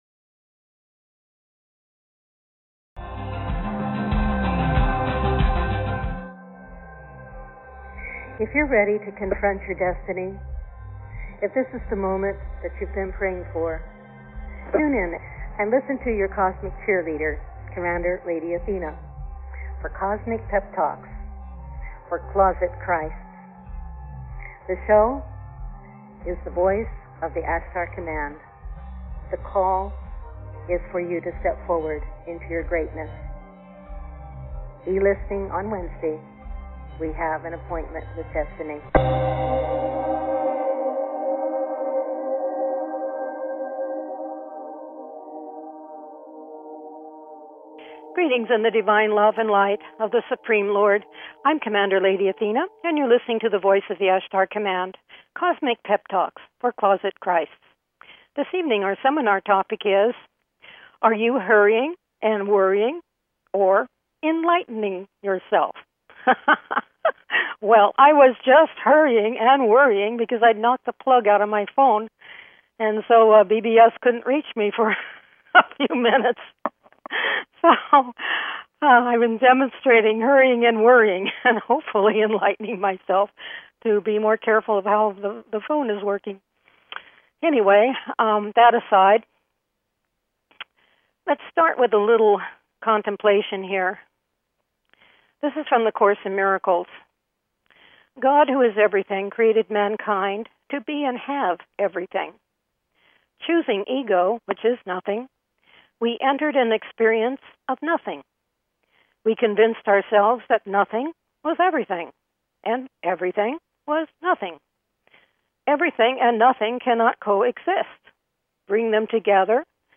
Talk Show Episode, Audio Podcast, The Voice of the Ashtar Command and ARE YOU HURRYING & WORRYING OR ENLIGHTENING YOURSELF? on , show guests , about Sai Baba,Jesus,Krishna,Enlightenment,God's Duty,global transformation,fostering of devotees & famiily members,God the only DOER,Detachment,Liberation, categorized as History,Physics & Metaphysics,Variety